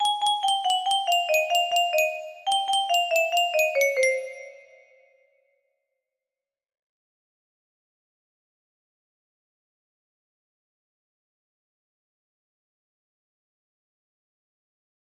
Clone of Alborea music box melody
Grand Illusions 30 (F scale)